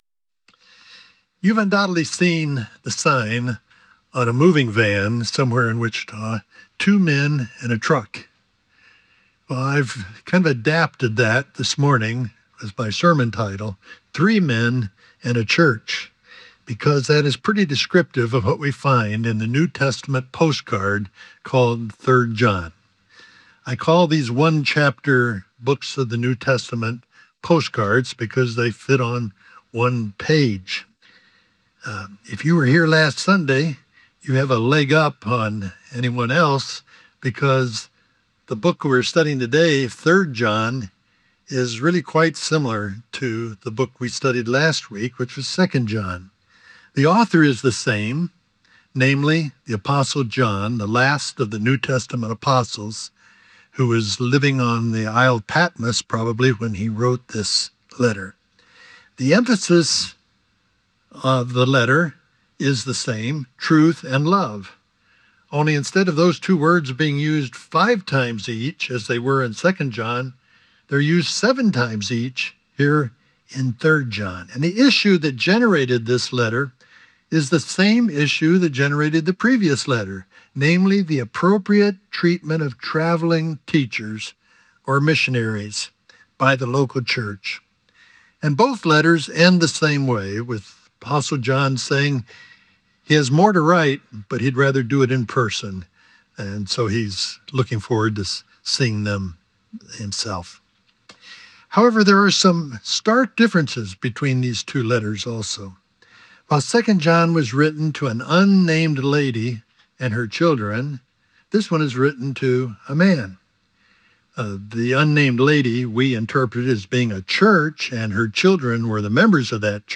Three Men and a Church TO HEAR THE ENTIRE SERMON CLICK THE LISTEN BUTTON ABOVE
PLACE: Beacon Church in Goddard, KS.